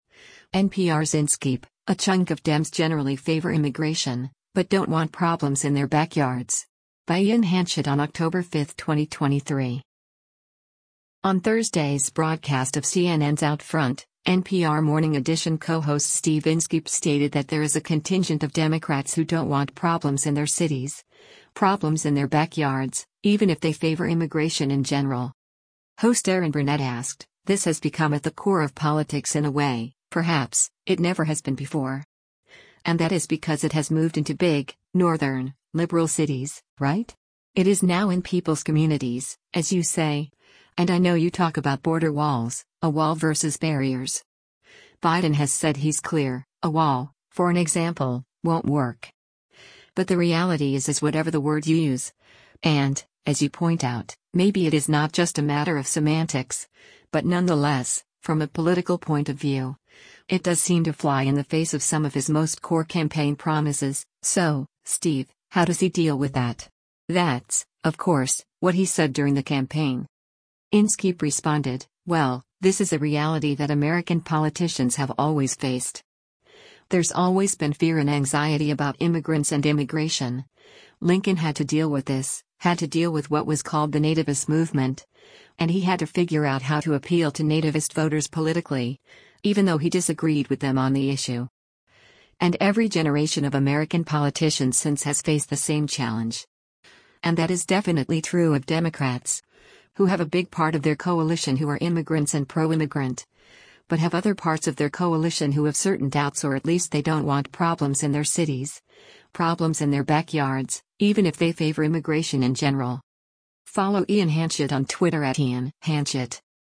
On Thursday’s broadcast of CNN’s “OutFront,” NPR “Morning Edition” co-host Steve Inskeep stated that there is a contingent of Democrats who “don’t want problems in their cities, problems in their backyards, even if they favor immigration in general.”